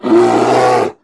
Sound / sound / monster / bear / attack_1.wav